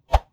Close Combat Swing Sound 69.wav